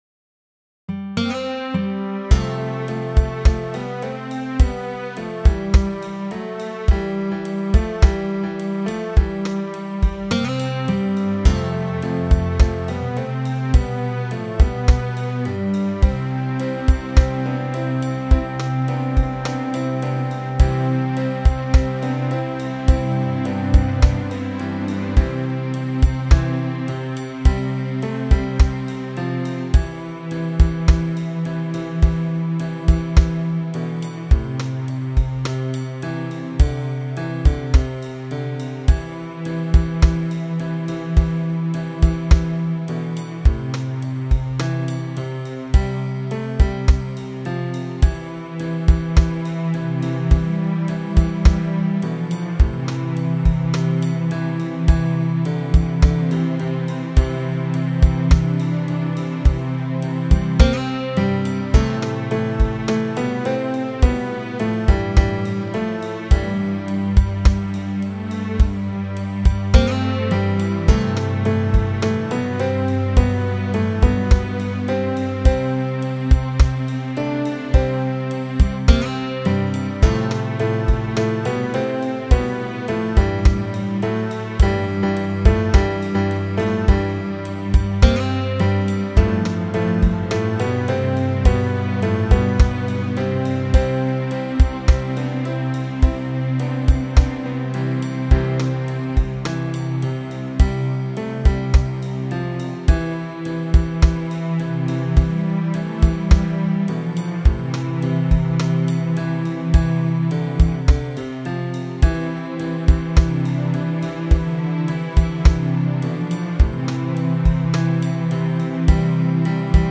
卡拉OK版 ，均為MP3 格式、約 4MB
ageratum_conyzoides_instument.mp3